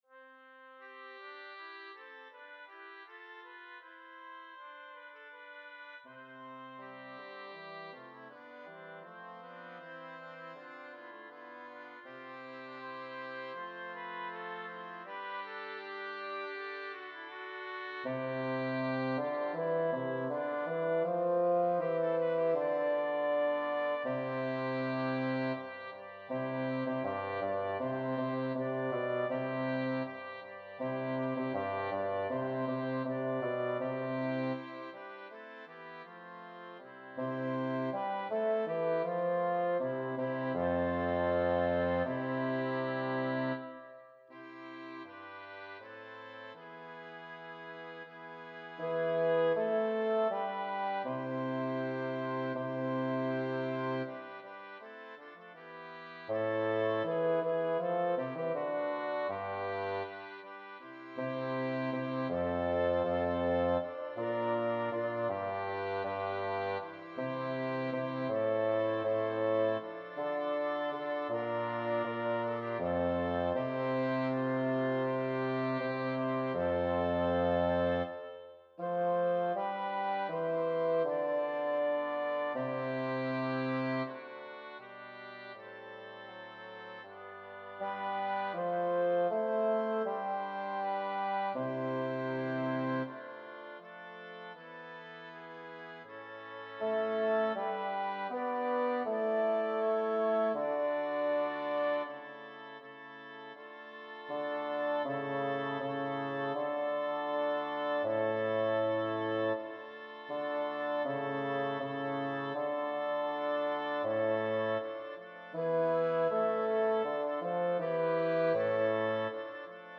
Bas 2
Raphaella-Aleotti_Surge-propera-amica-mea_B2.mp3